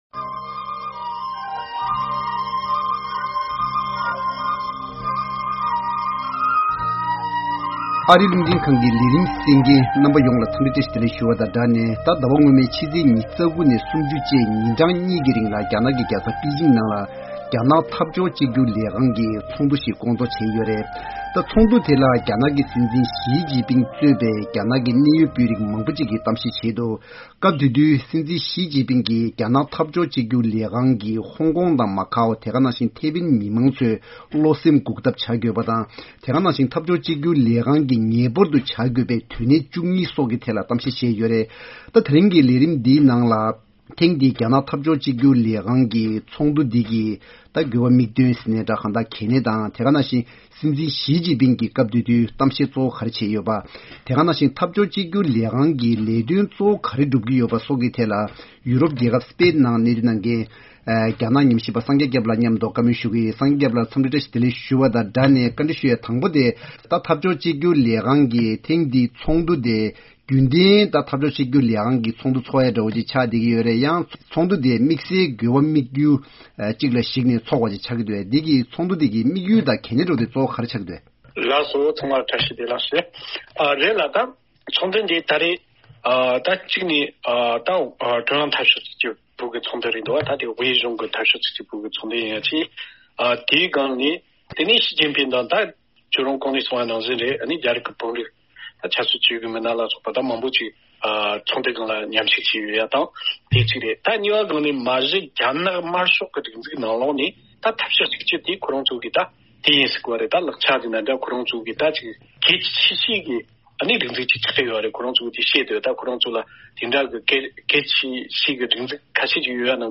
ཐེངས་འདིའི་བགྲོ་གླེང་མདུན་ཅོག་ལས་རིམ་གྱིས་ཉེ་ལམ་རྒྱ་ནག་གི་རྒྱལ་ས་པི་ཅིང་ནང་འཐབ་ཕྱོགས་གཅིག་གྱུར་ལས་ཁང་གི་ཚོགས་འདུ་ཞིག་ཐོག་ཞི་ཅིང་པིན་གྱིས་གཏམ་བཤད་བྱས་ཡོད་པ་དང་། འཐབ་ཕྱོགས་གཅིག་གྱུར་ལས་ཁང་གི་ལས་དོན་གང་ཡིན་པ་བཅས་ཀྱི་སྐོར་་ལ་གླེང་མོལ་བྱས་ཡོད།